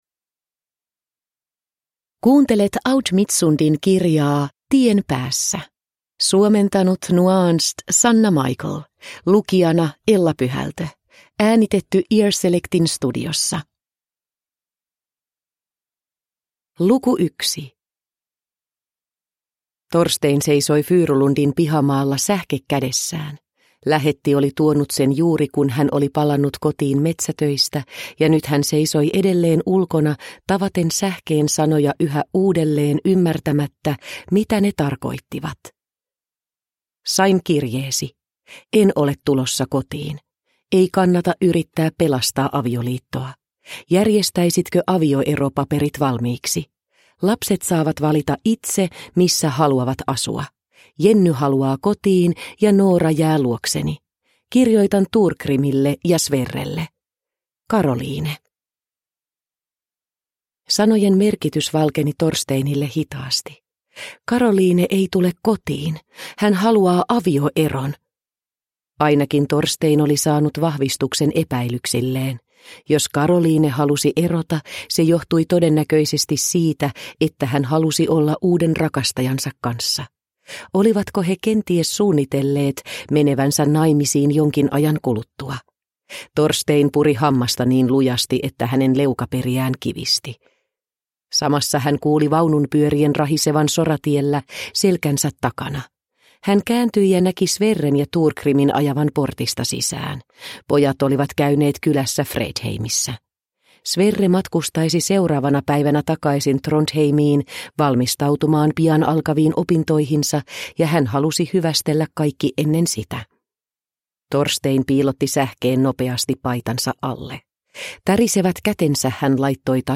Tien päässä (ljudbok) av Aud Midtsund